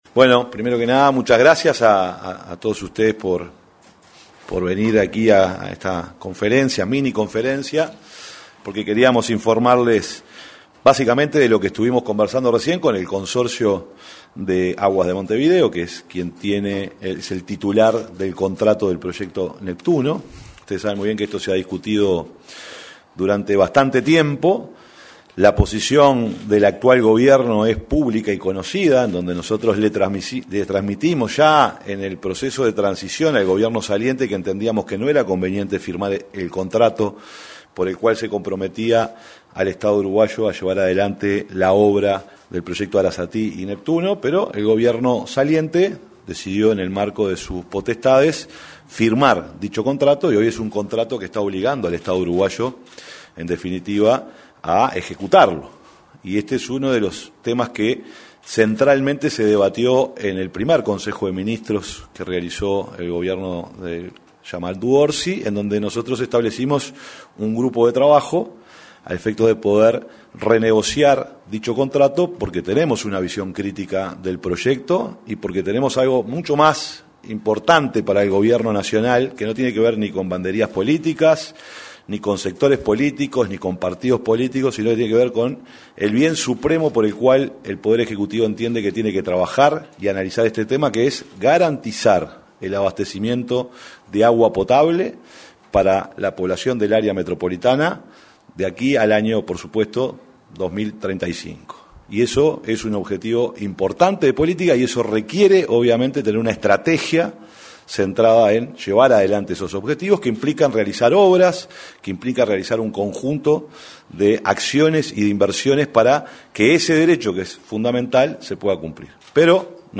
Secretario de la Presidencia de la República, Alejandro Sánchez, en conferencia de prensa
Este viernes 11, se realizó en la Torre Ejecutiva una conferencia de prensa, en la que se expresó el secretario de la Presidencia de la República,